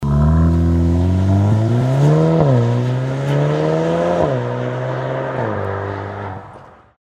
AXLE-BACK-SYSTEM
Seat_Cupra_Ateca_OPF_BJ19_REMUS_Axle_Back_Klappe_geöffnet.mp3